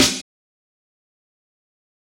Snare Shooter 8.wav